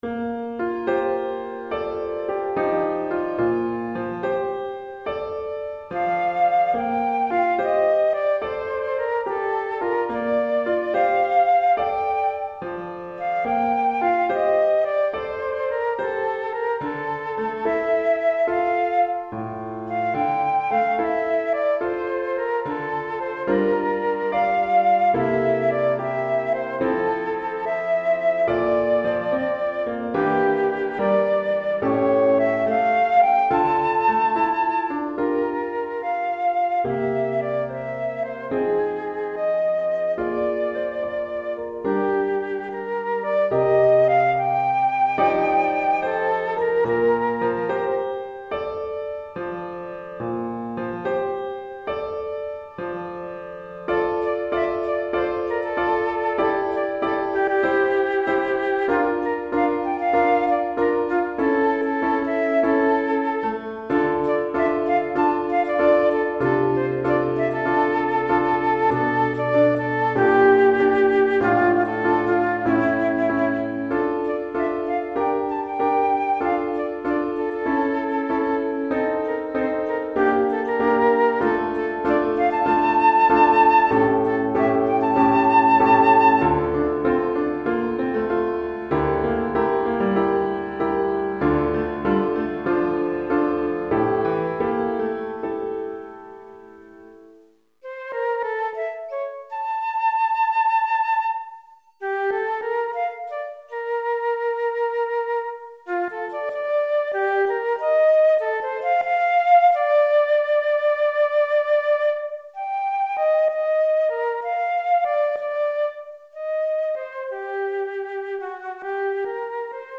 Pour flûte et piano DEGRE CYCLE 1